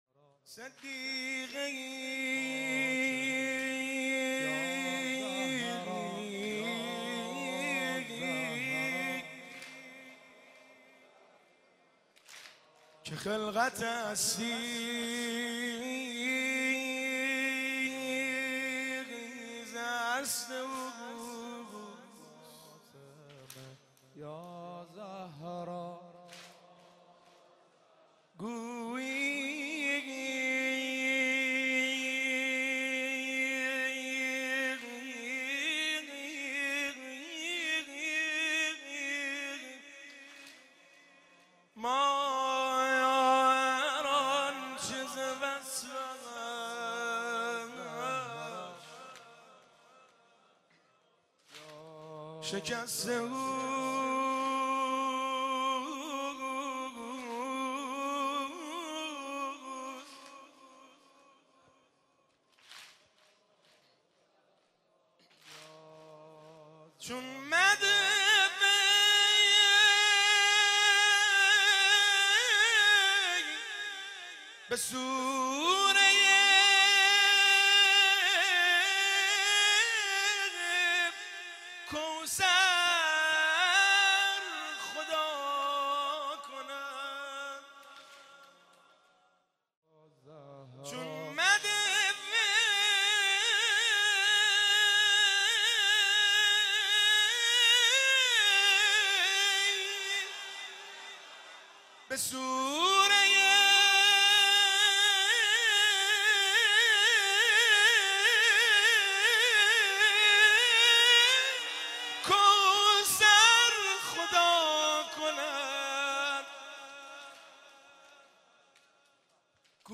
مجموعه نوحه های جلسه هفتگی
که در هیئت بین الحرمین تهران اجرا شده است
من از تب و تاب حرم آقا،بیمارم بیمارم ( شور )